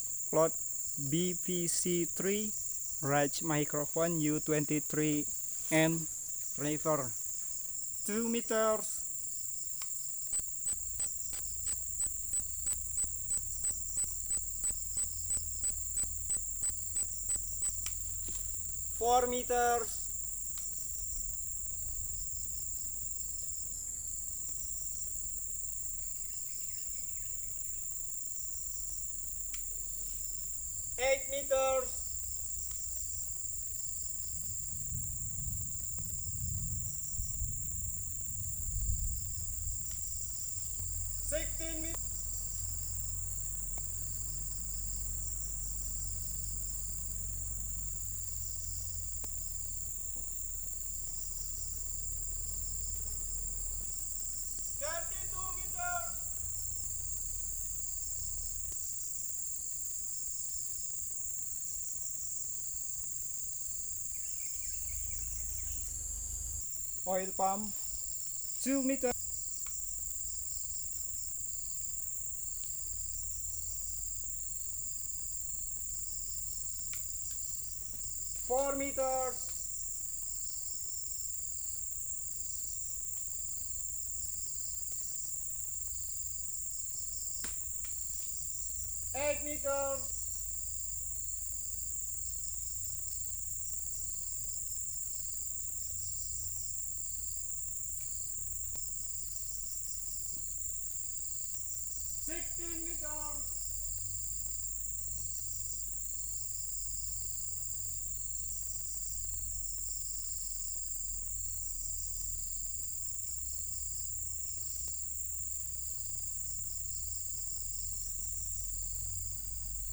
Bat point counts and automated recordings 2019